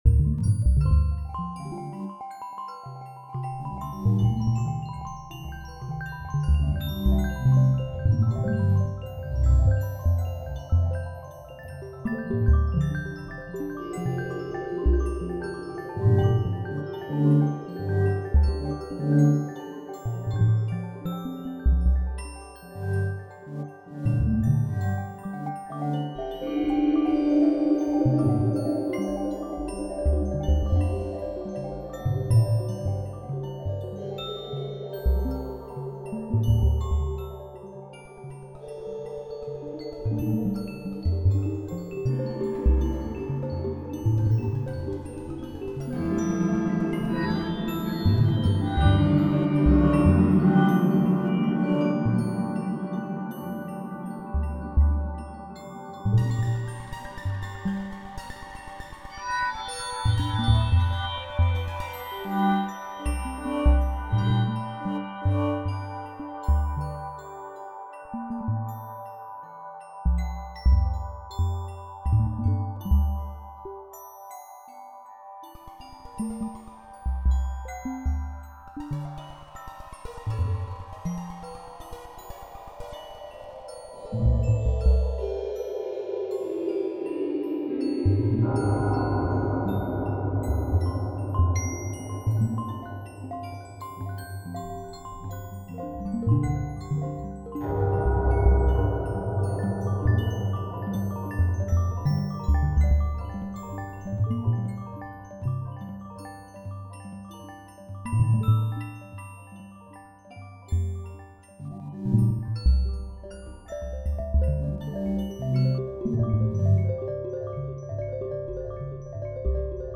Ableton's "Collision" instrument
Collision instrument with Sonic Faction’s Probability Pack randomizers through various echoes/delays for an atonal random stew. Each clip's loop length is different, so they cycle against each other.